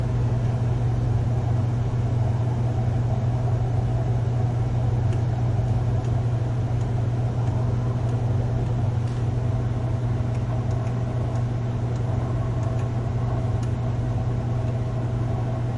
自助洗衣店 " 自助洗衣店的洗衣机 洗衣机的隆隆声4
描述：洗衣店洗衣机rumble4.flac
Tag: 洗涤 洗衣店 垫圈 隆隆声